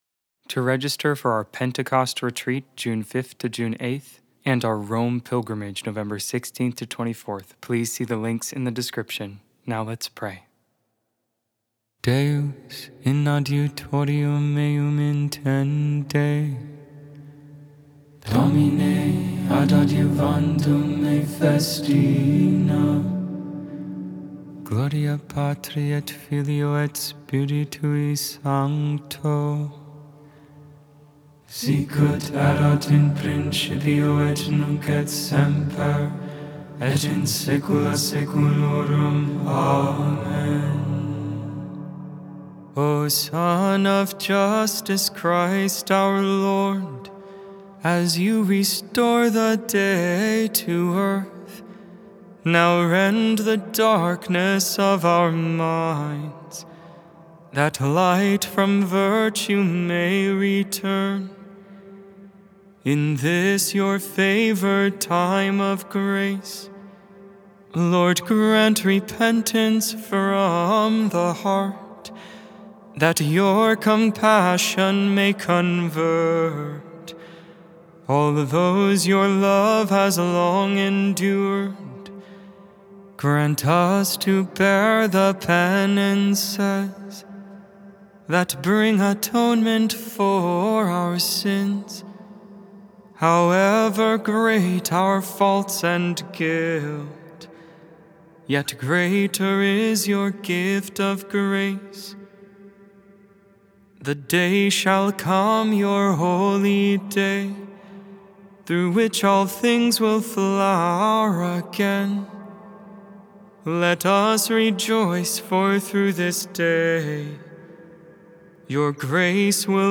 Lauds, Morning Prayer for Wednesday of the 4th week in Lent, April 1, 2025.